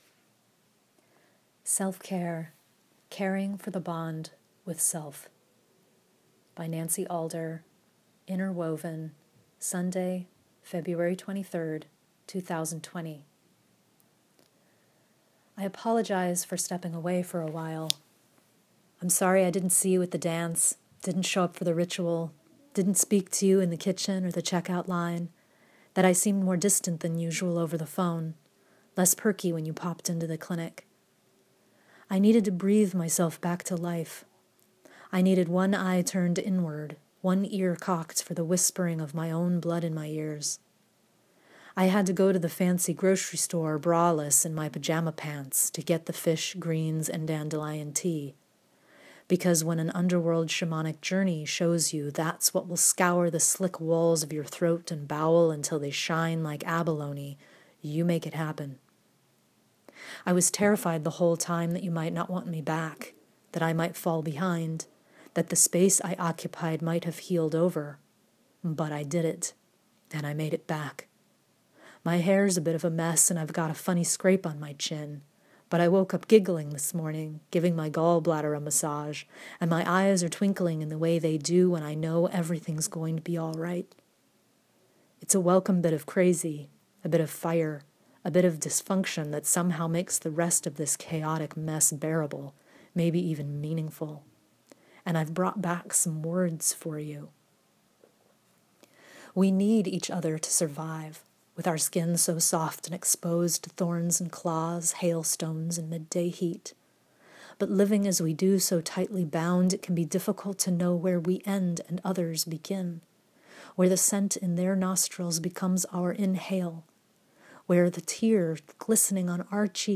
Let me read to you by clicking the link above and enjoy this 9-minute listen!